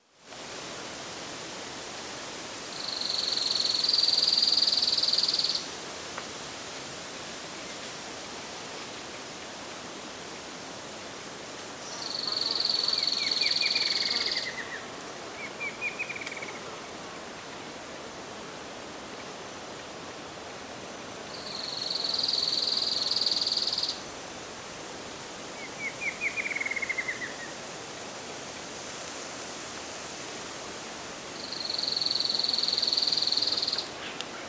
Tiluchi Enano (Terenura maculata)
Misiones_2014oct_499---Tiluchi-enano.mp3
se escucha también un Tiluchi Ala Rojiza
Nombre en inglés: Streak-capped Antwren
Localidad o área protegida: Parque Nacional Iguazú
Certeza: Vocalización Grabada